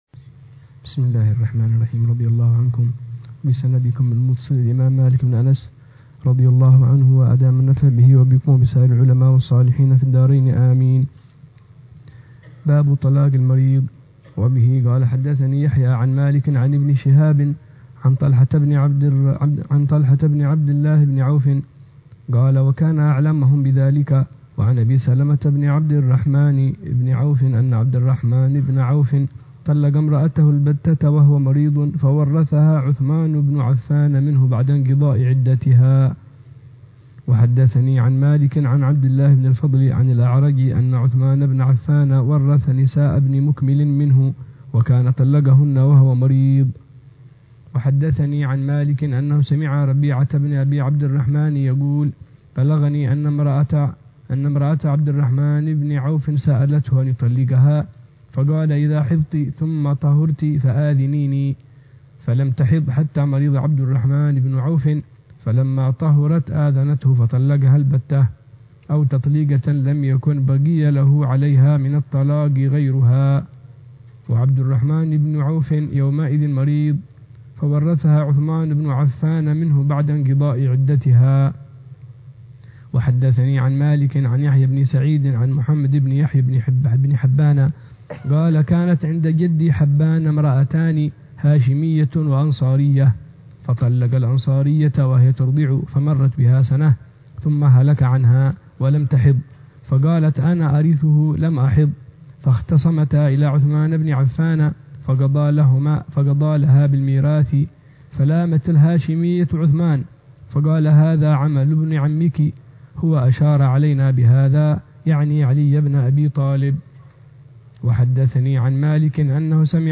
شرح العلامة الحبيب عمر بن محمد بن حفيظ على كتاب الموطأ لإمام دار الهجرة الإمام مالك بن أنس الأصبحي، برواية الإمام يحيى بن يحيى الليثي،